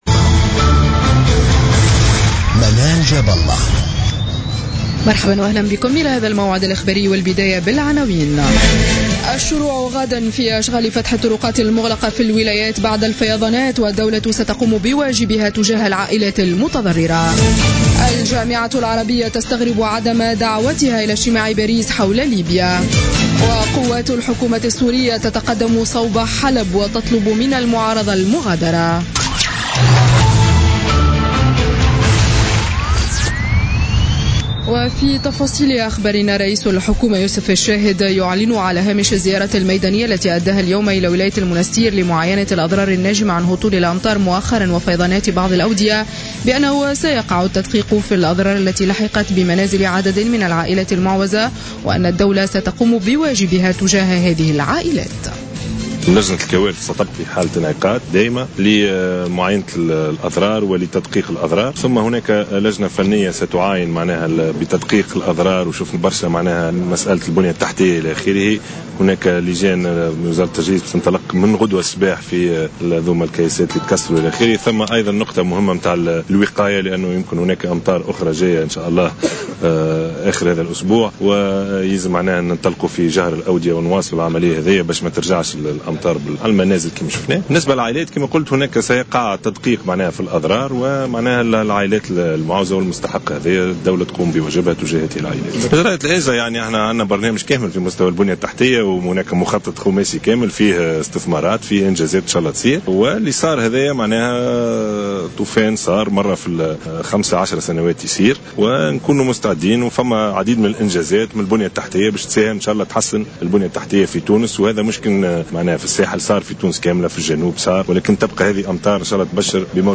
نشرة أخبار السابعة مساء ليوم الأحد 2 أكتوبر 2016